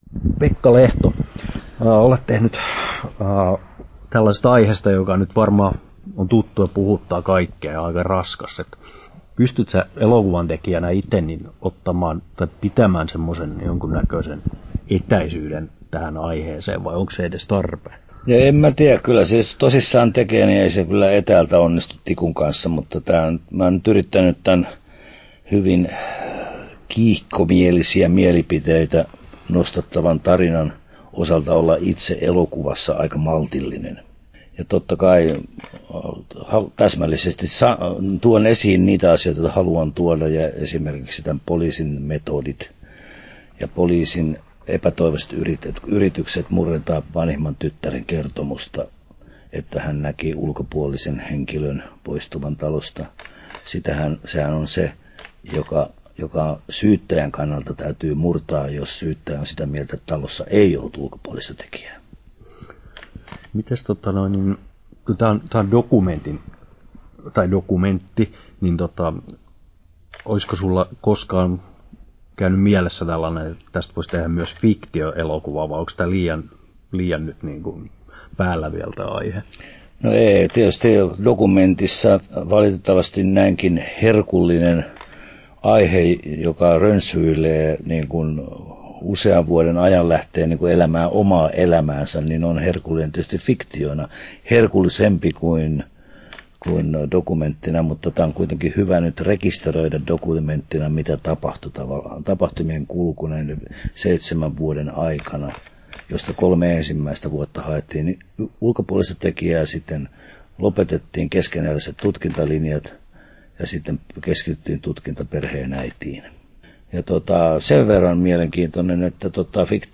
Turku Toimittaja